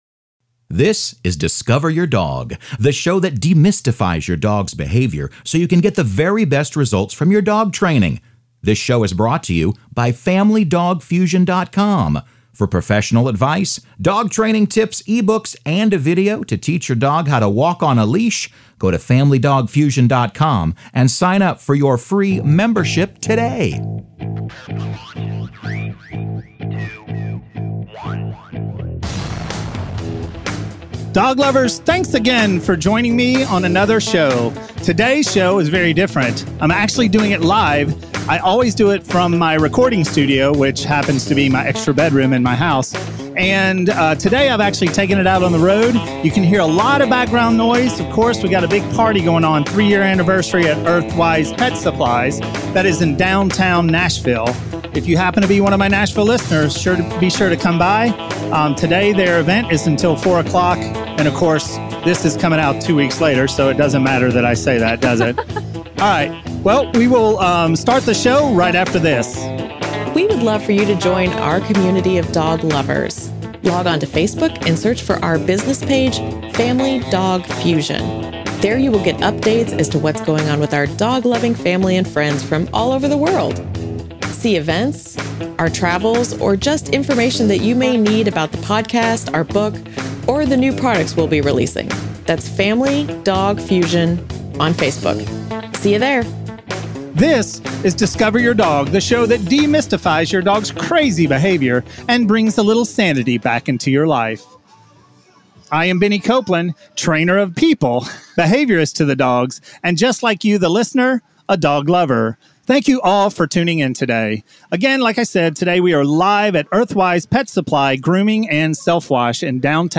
Ep 209 Live Recording at Earthwise Pet Supply - FamilyDogFusion